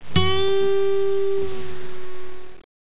The third bend is a bend-and-release of 1/2 step on the second string 7th fret.
This is accomplished in the same fashion as a normal bend, but when the release in indicated, you gently release the string and allow it to go back down to the original pitch.